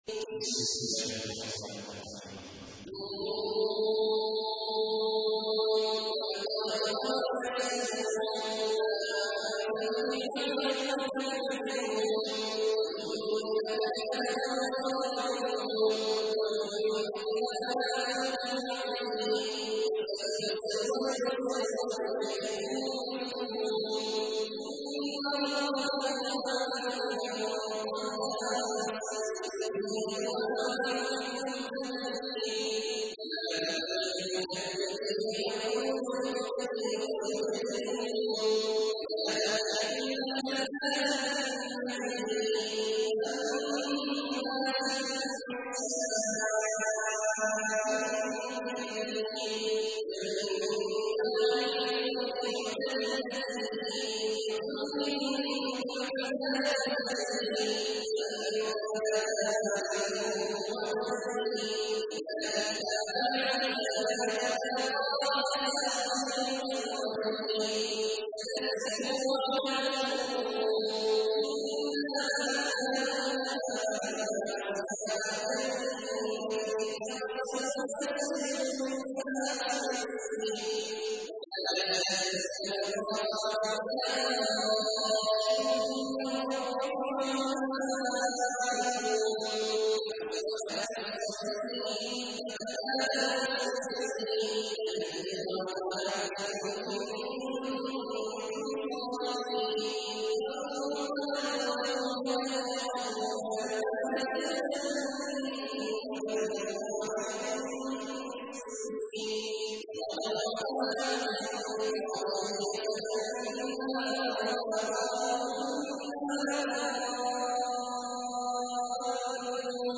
تحميل : 68. سورة القلم / القارئ عبد الله عواد الجهني / القرآن الكريم / موقع يا حسين